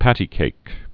(pătē-kāk)